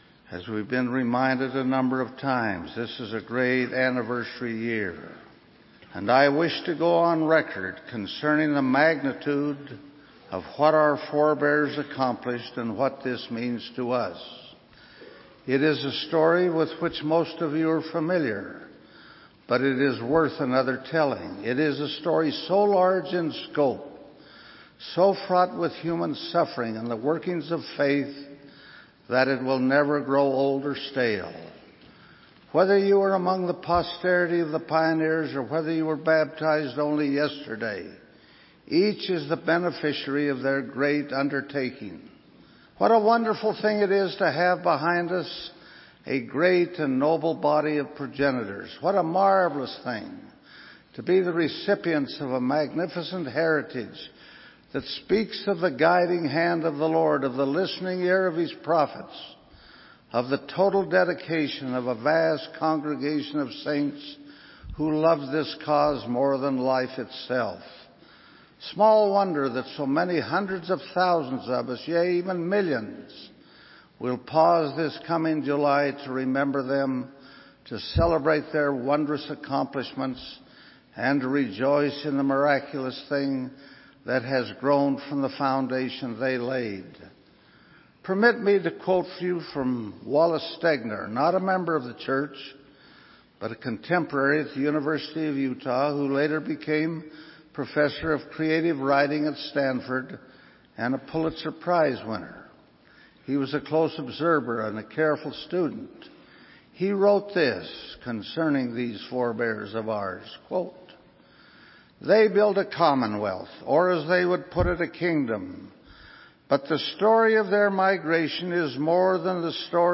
Conference Reports